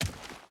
Dirt Run 4.ogg